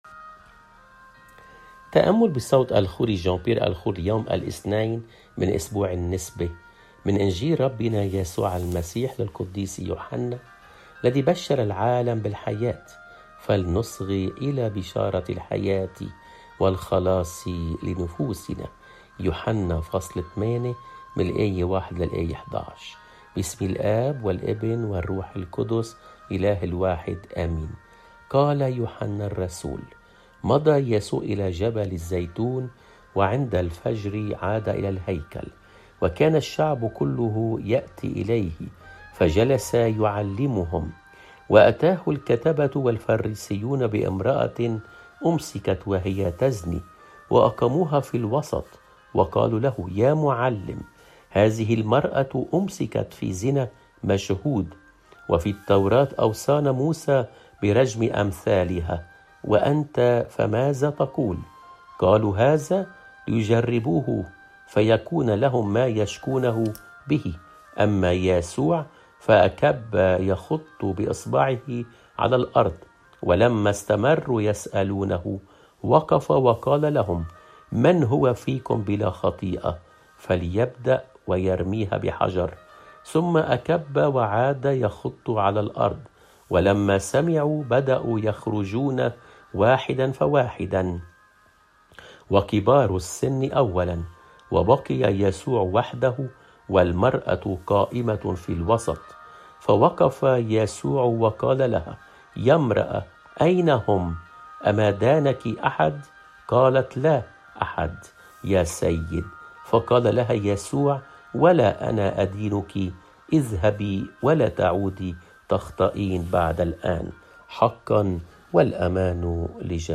قراءات روحيّة صوتيّة - Yasou3na